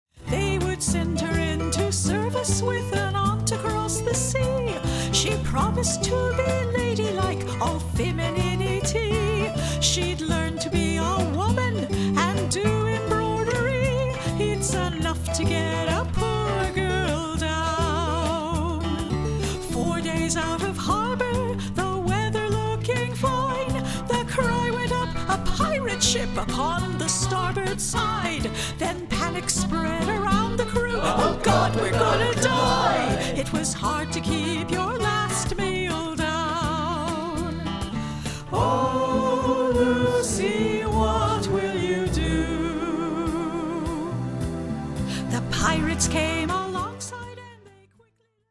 ♫  Play sample from a late mixdown